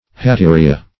Hatteria \Hat*te"ri*a\ (h[a^]t*t[=e]"r[i^]*[.a]), n. [NL.]